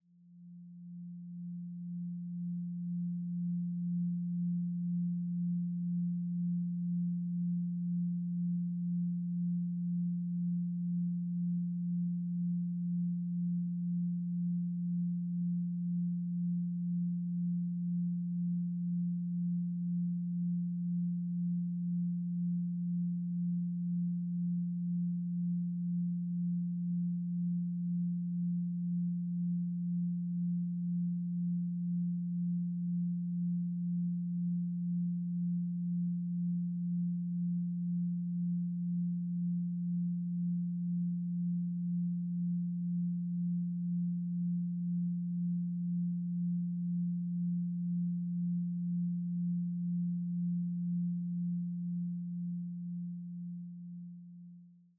Duration: 0:55 · Genre: Synthwave · 128kbps MP3